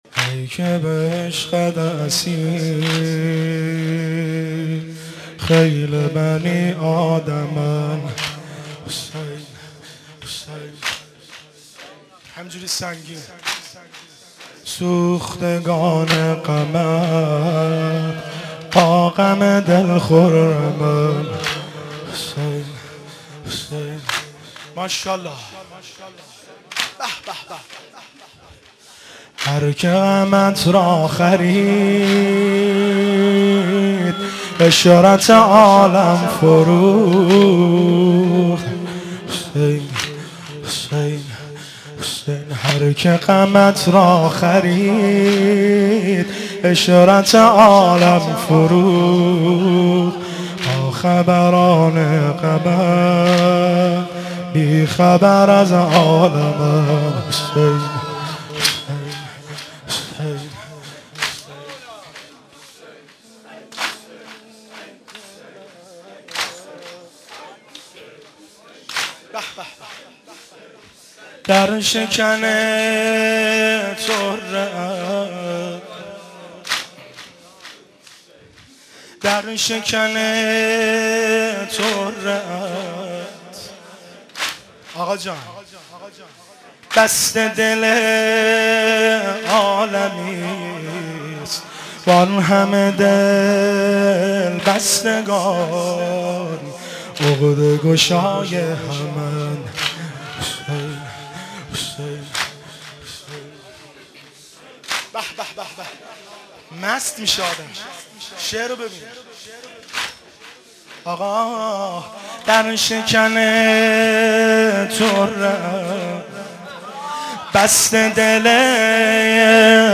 زمینه، روضه، مناجات